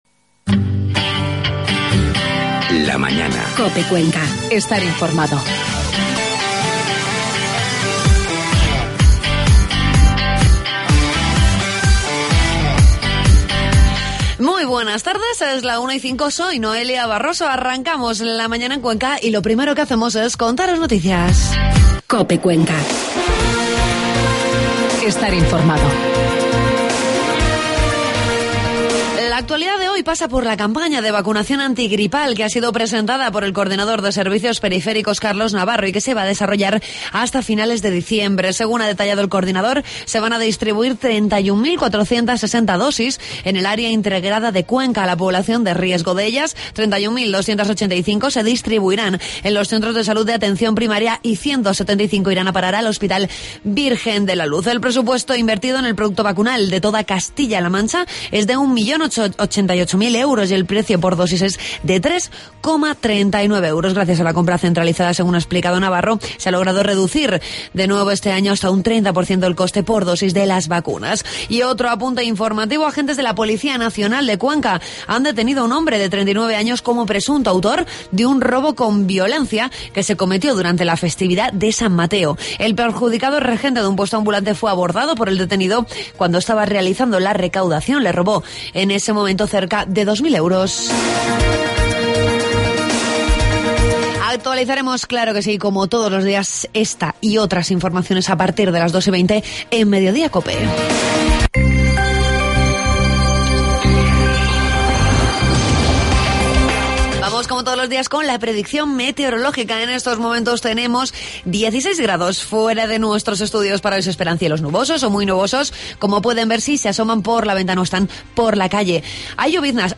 Entrevistamos al presidente de la Diputación Provincial, Benjamín Prieto, con el que tratamos diferentes asuntos de actualidad como el ATC o la UCLM.